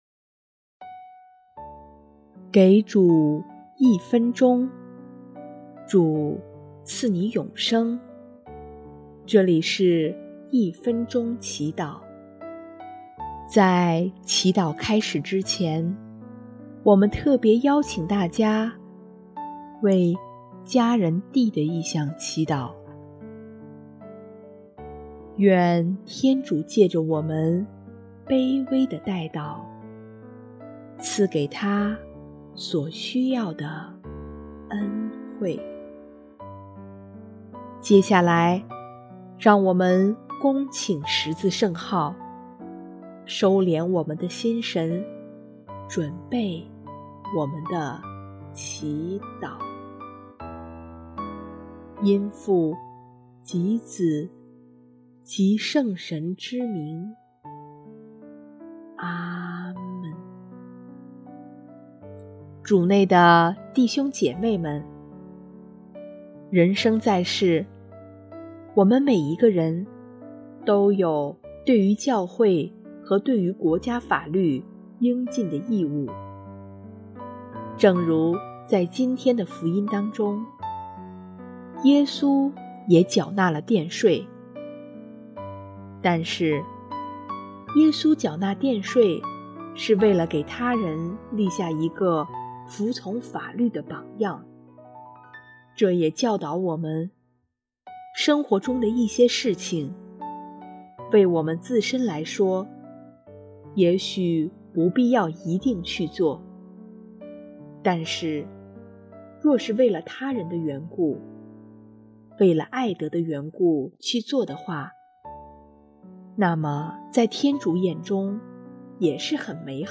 音乐： 第三届华语圣歌大赛参赛歌曲《主，爱你》（D：求主赐予自己坚固的信德）